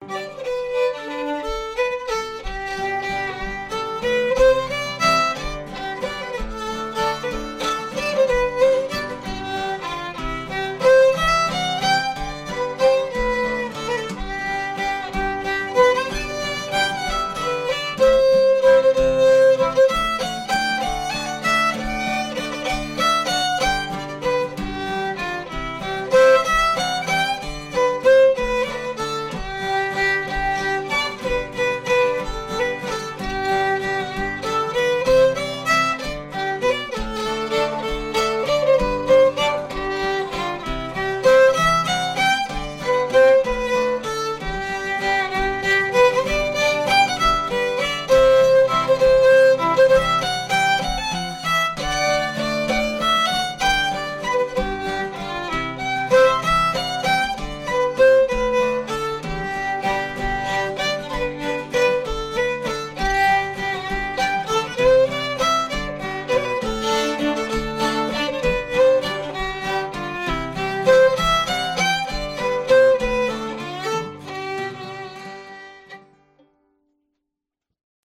gtr